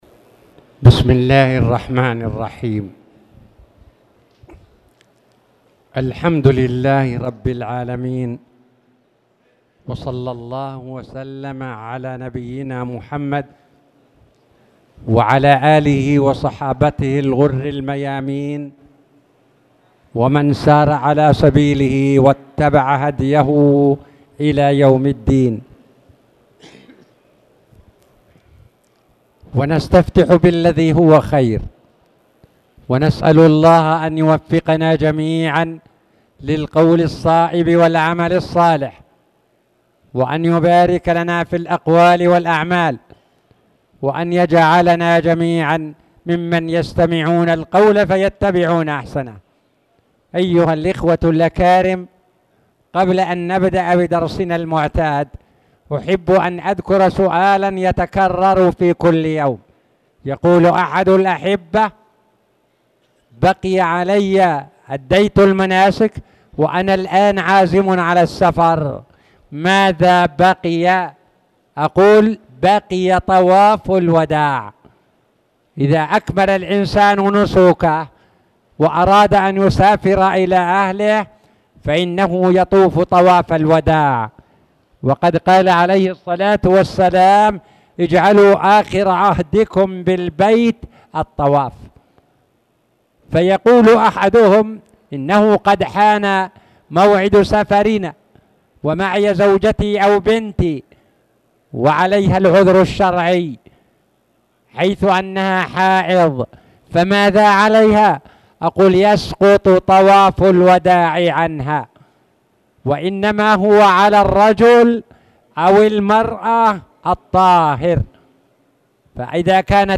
تاريخ النشر ٢٦ ذو الحجة ١٤٣٧ هـ المكان: المسجد الحرام الشيخ